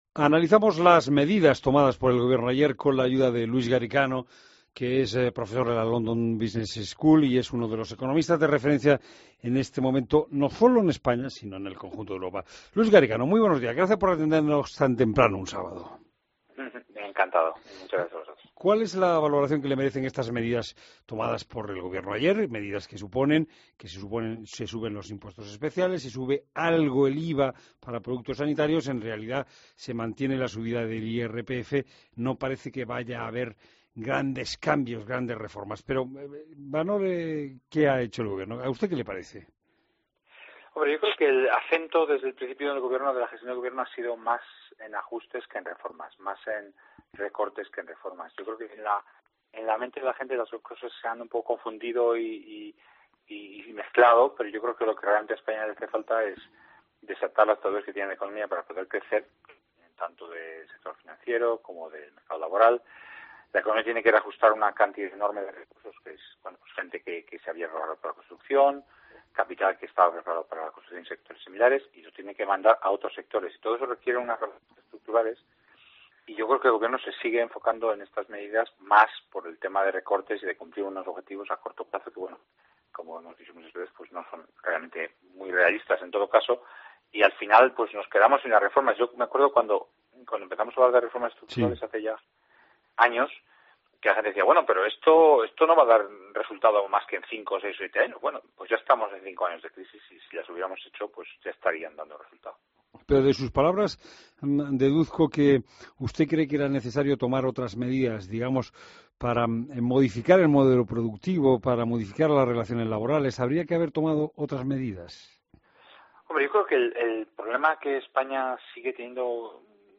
Entrevista a Luis Garicano, profesor de la London School of Economics